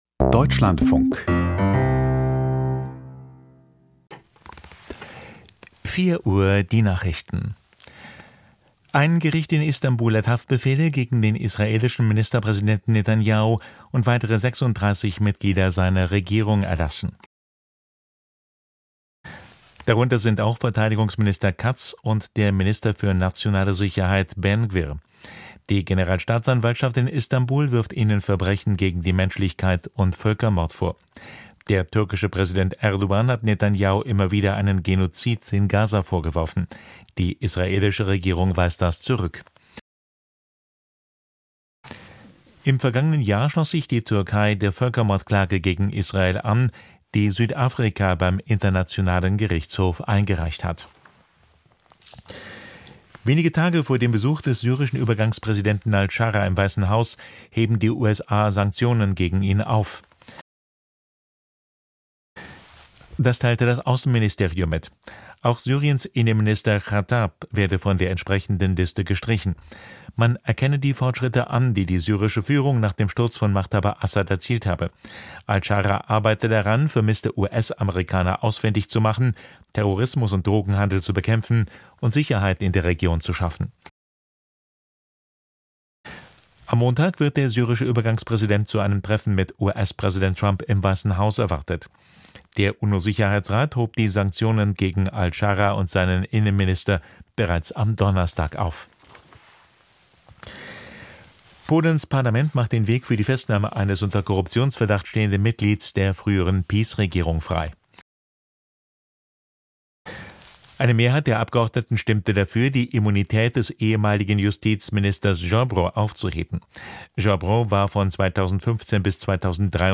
Die Nachrichten vom 08.11.2025, 04:00 Uhr